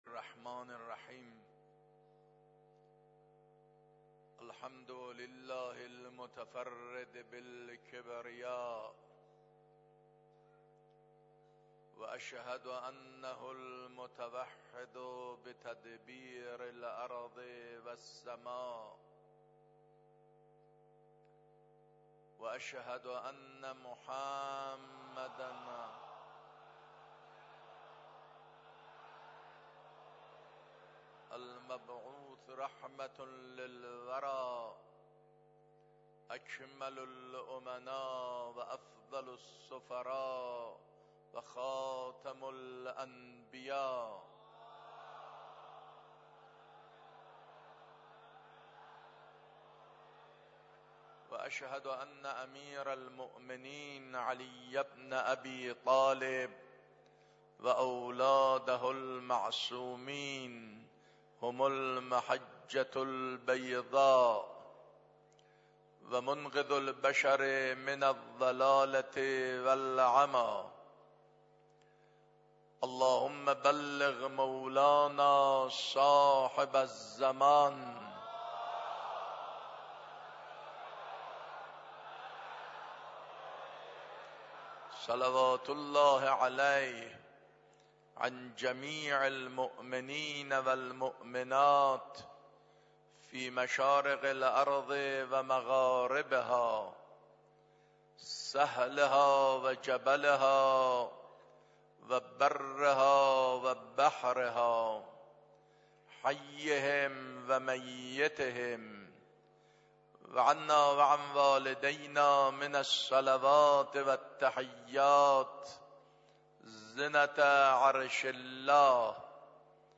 خطبه اول نماز جمعه 16 آبان ماه 93.mp3
خطبه-اول-نماز-جمعه-16-آبان-ماه-93.mp3